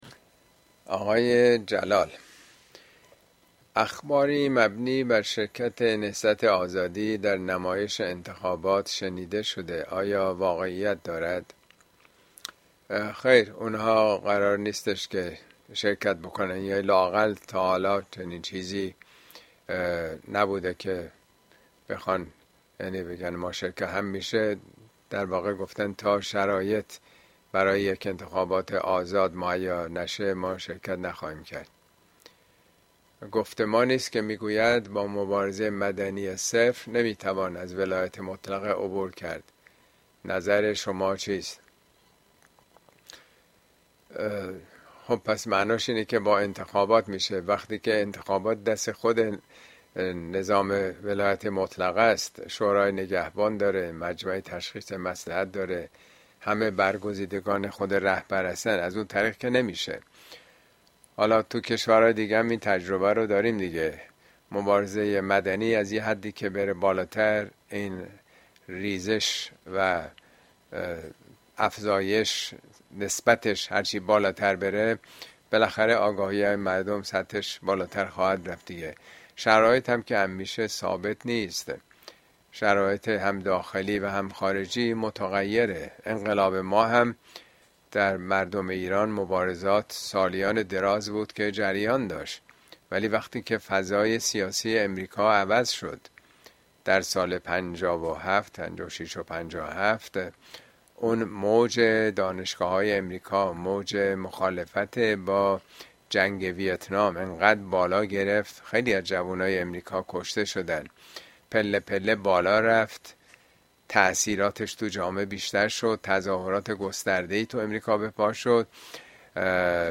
` موضوعات اجتماعى اسلامى !خیمه شب بازی انتخابات اين سخنرانى به تاريخ ۵ جون ۲۰۲۴ در كلاس آنلاين پخش شده است توصيه ميشود براىاستماع سخنرانى از گزينه STREAM استفاده كنيد.